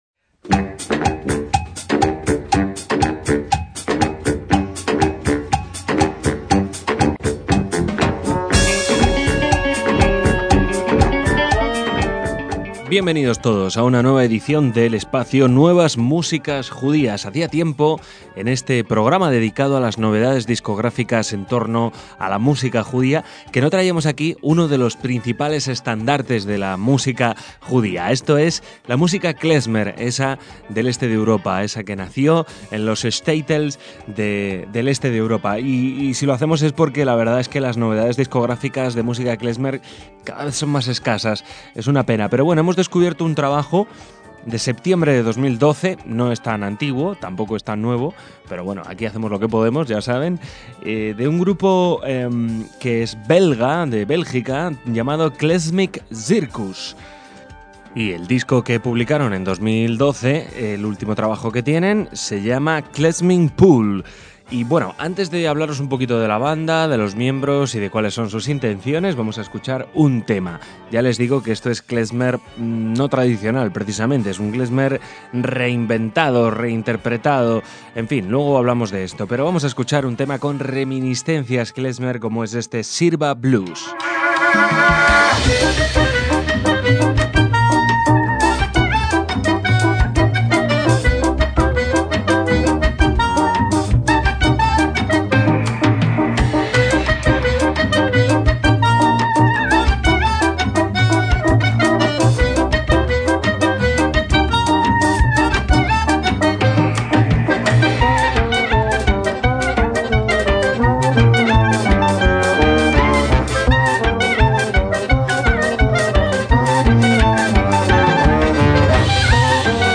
saxo alto, clarinete bajo y clarinete
trombón y tuba
acordeón
guitarra y contrabajo
percusiones